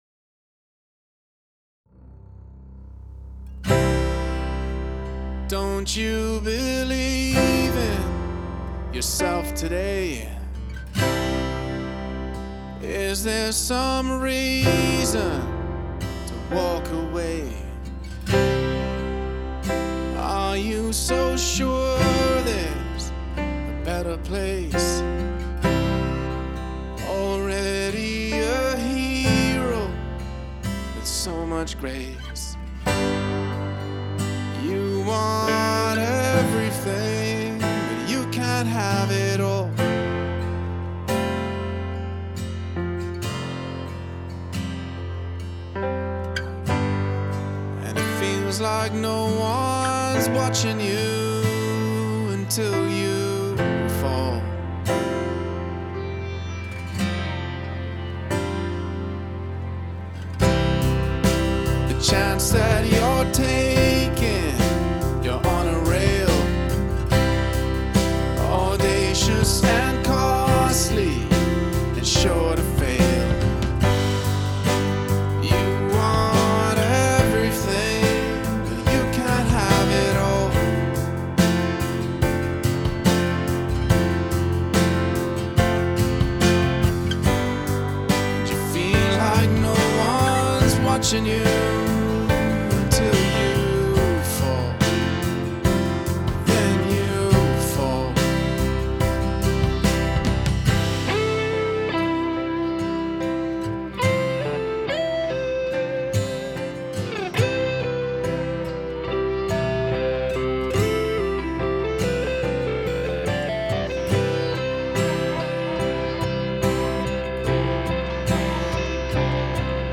alt/rock/world production